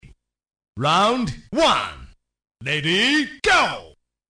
SFXround1 ready go下载音效下载
SFX音效